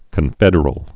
(kən-fĕdər-əl, -fĕdrəl)